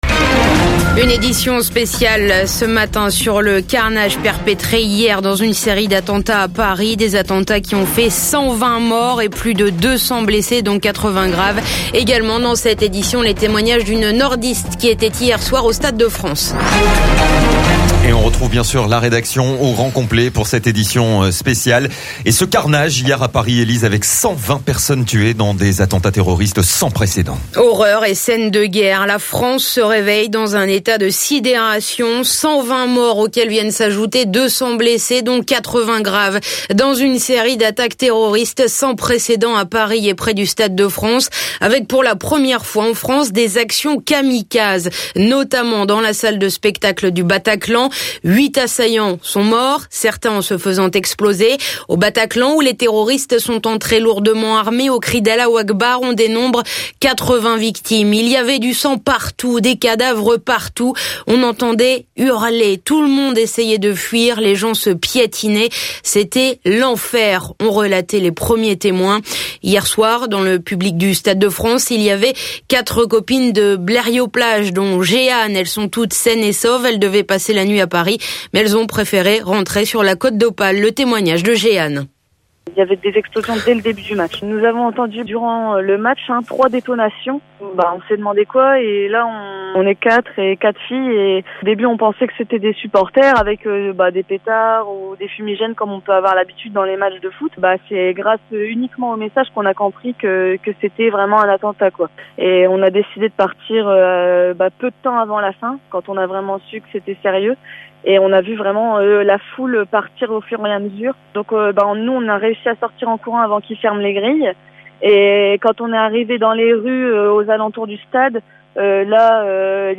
Attentats de Paris 120 morts 200 blessés : édition spéciale avec la rédaction RADIO 6 (journal de 8h)